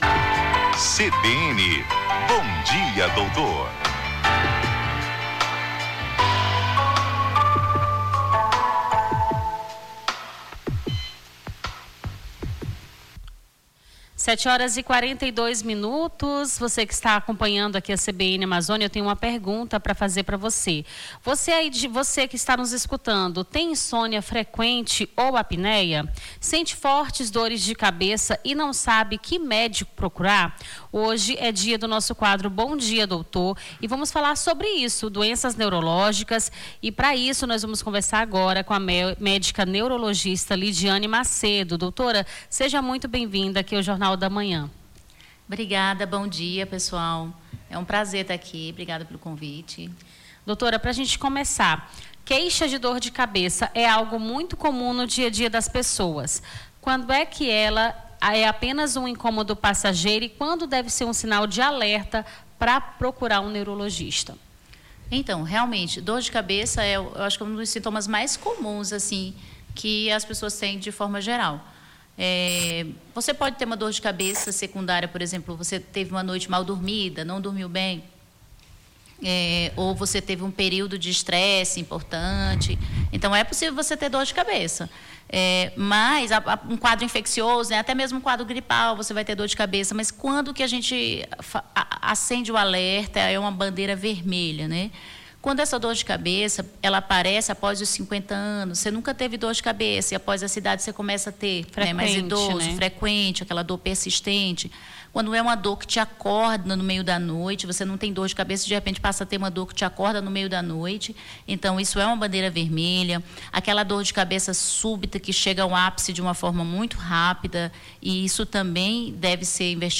Bom Dia Doutor: médica esclarece dúvidas sobre doenças neurológicas